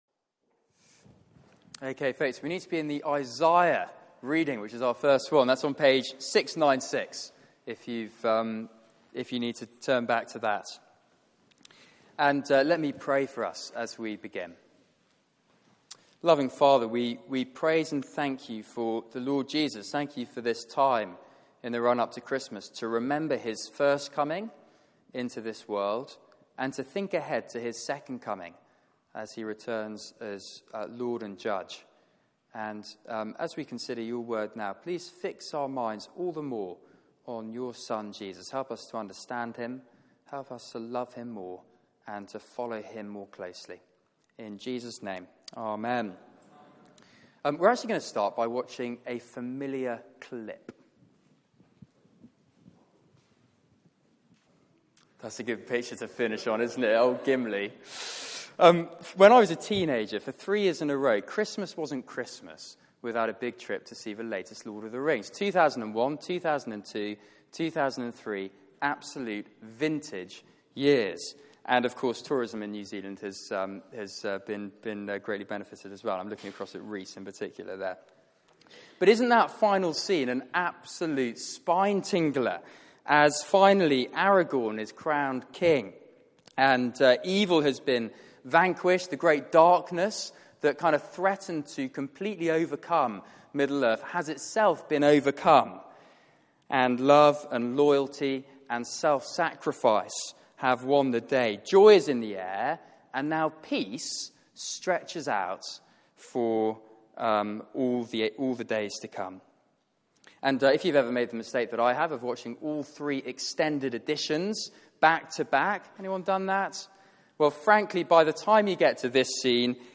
Service Type: Weekly Service at 4pm